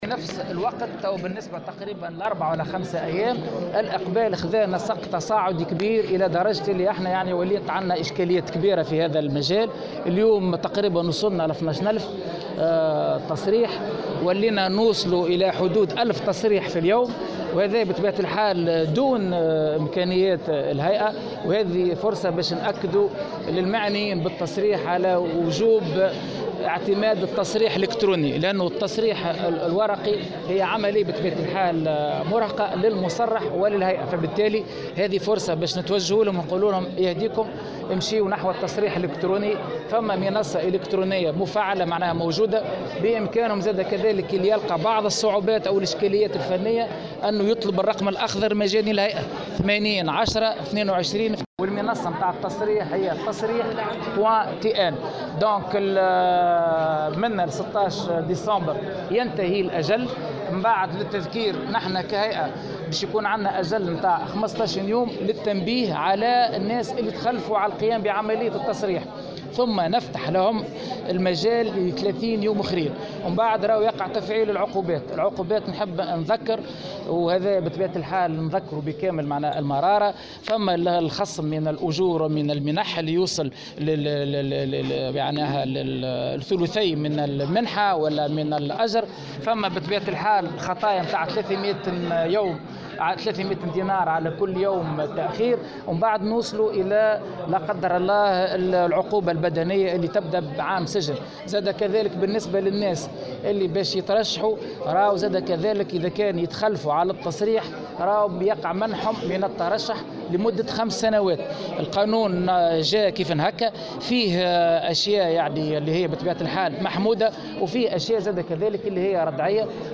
وأضاف في تصريح لمراسلة "الجوهرة أف أم" أنه بإمكان المعنيين التصريح عبر المنظومة الالكترونية، مشيرا إلى أنه سيتم تفعيل العقوبات بعد انتهاء الآجال المحدّدة من ذلك الخصم من الاجور والمنح (ثلثي المنحة أو الأجر) و تسليط خطية قيمتها 300 د عن كل شهر تأخير في التصريح، وصولا إلى العقاب بالسجن لمدة سنة.